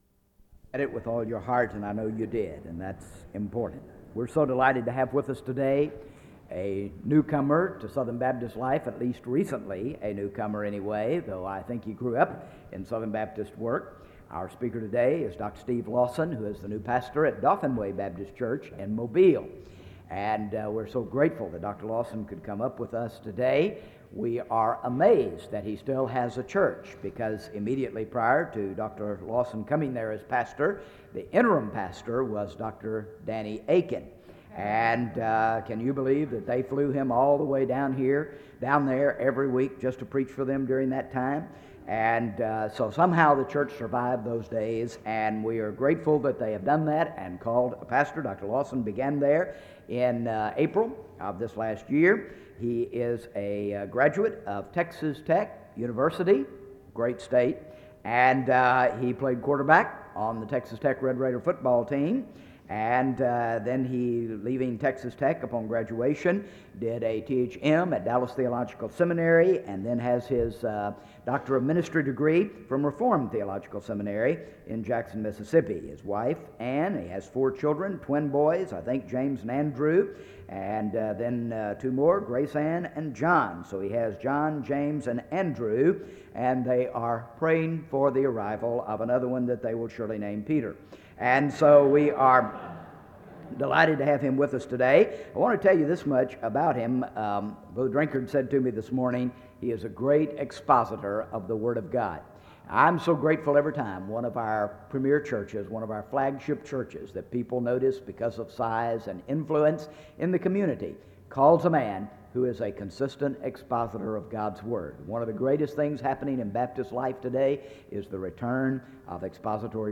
SEBTS Chapel and Special Event Recordings SEBTS Chapel and Special Event Recordings